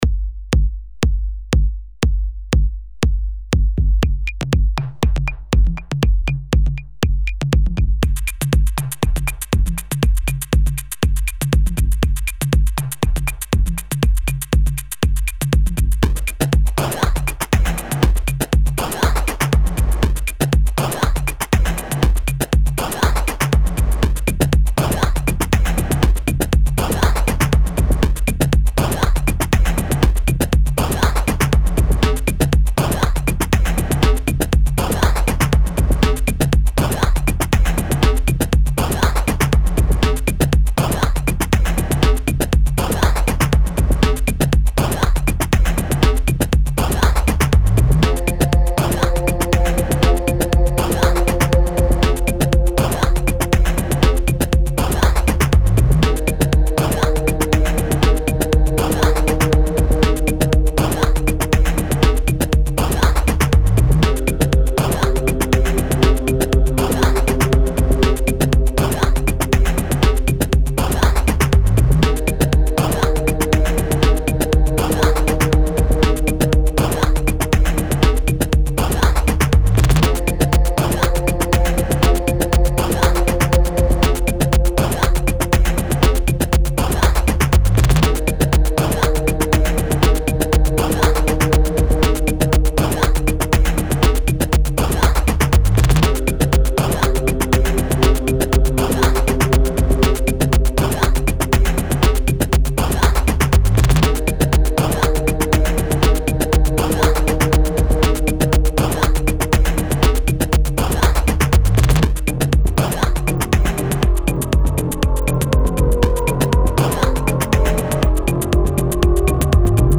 Genre Minimalism